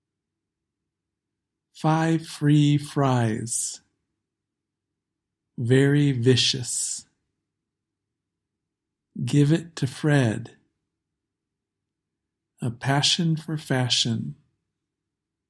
The /f/ and /v/ sounds
That means you make them by touching the top teeth to the bottom lip and blowing air through. /f/ is voiceless and /v/ is voiced, but the mouth position is the same.